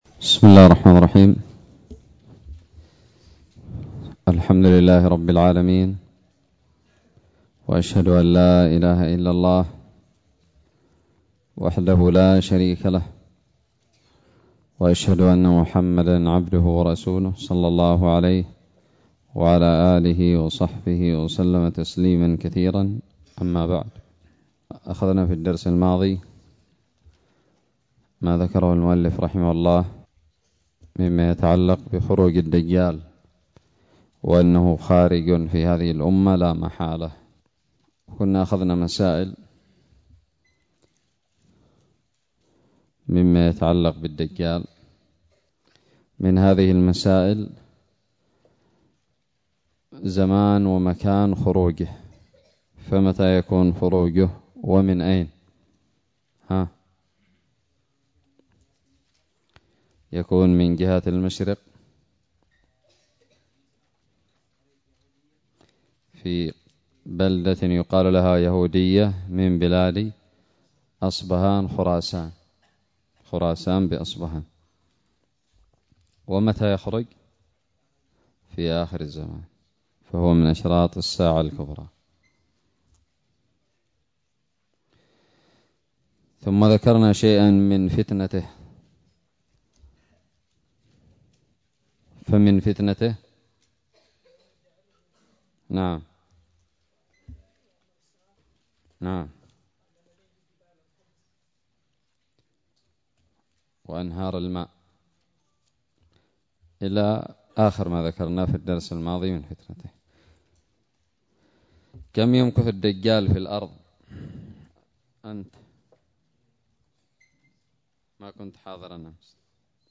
الدرس الثالث والثلاثون من شرح كتاب الاقتصاد في الاعتقاد للمقدسي
ألقيت بدار الحديث السلفية للعلوم الشرعية بالضالع